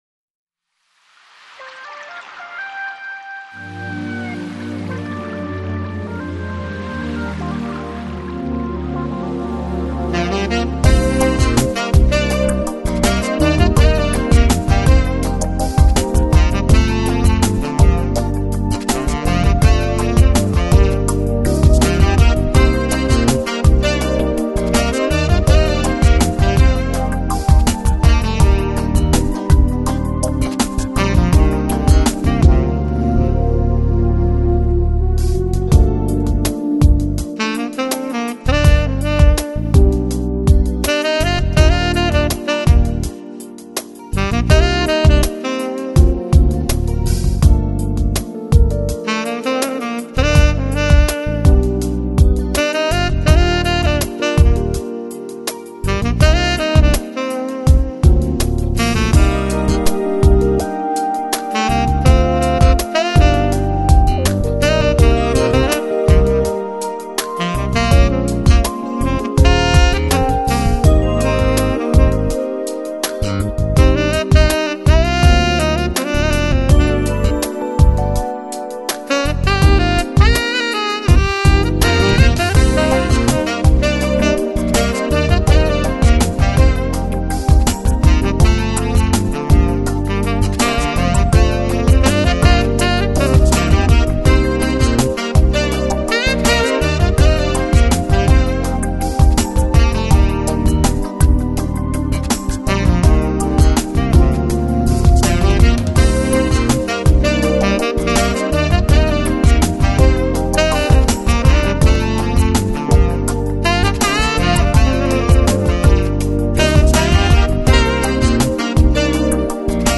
FLAC Жанр: Jazz Издание